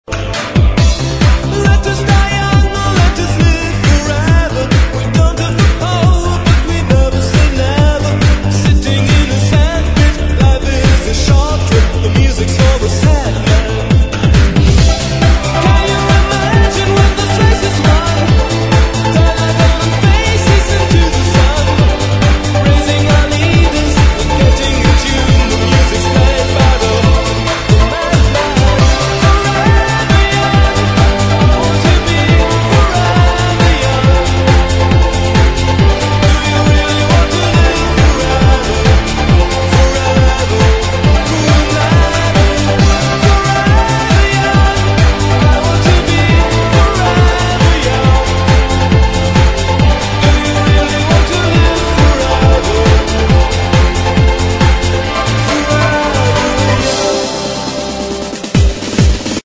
分类: MP3铃声
DJ 很有情调的男声